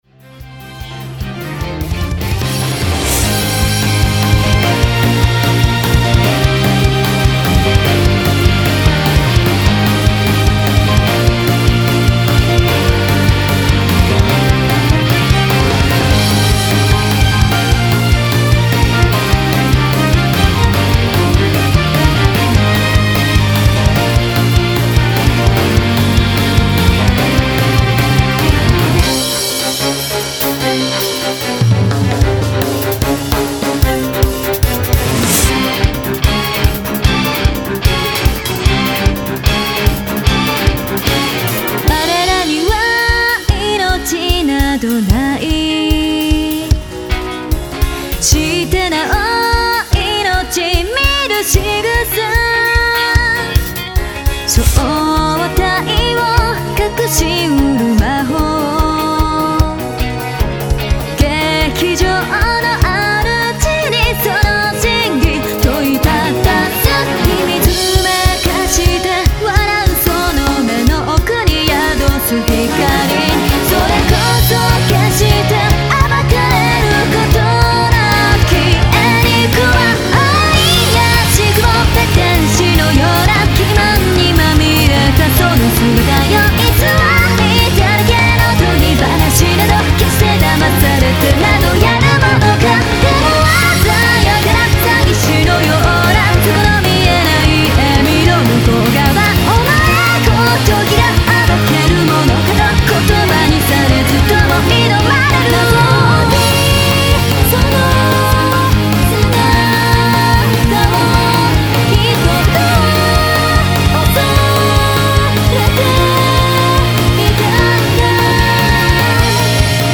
やや歌物を中心に、ジャンルはロックポップスをメインに、インストはやや怪しい雰囲気を中心に！